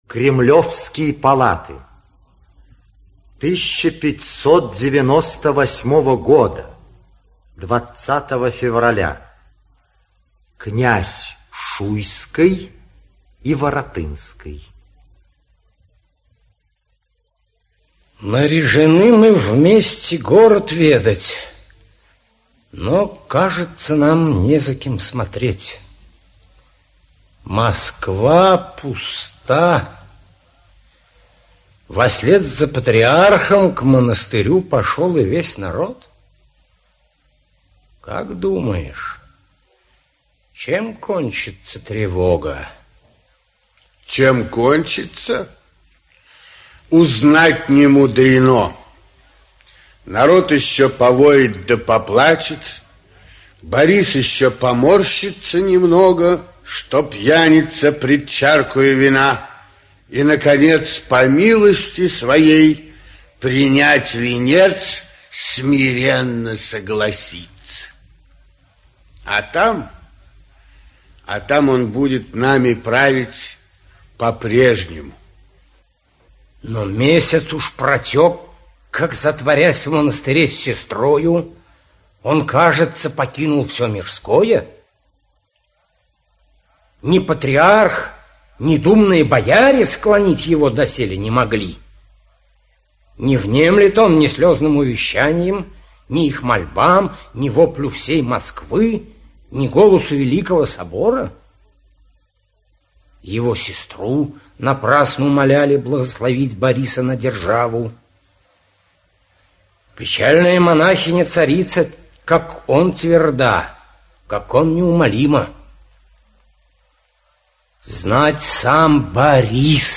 Аудиокнига Борис Годунов. Аудиоспектакль | Библиотека аудиокниг
Аудиоспектакль Автор Александр Пушкин Читает аудиокнигу Павел Массальский.